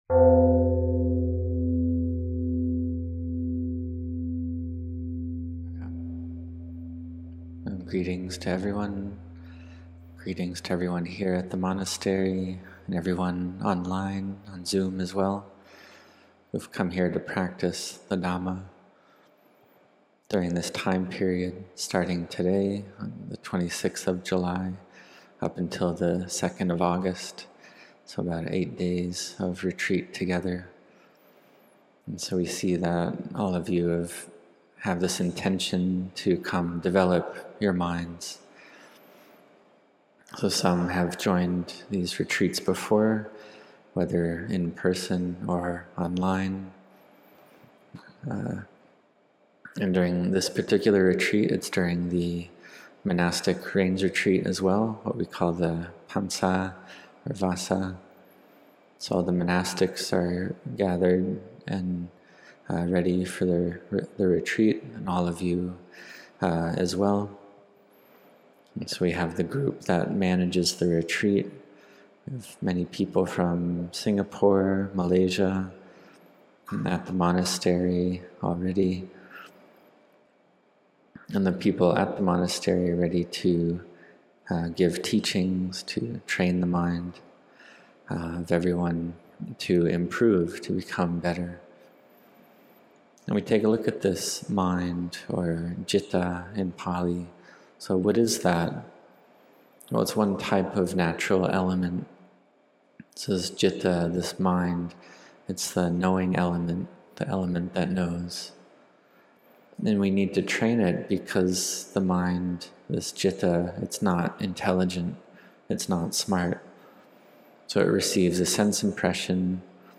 Generosity to Virtue to Peace | Retreat Day 1 Evening | 26 July 2025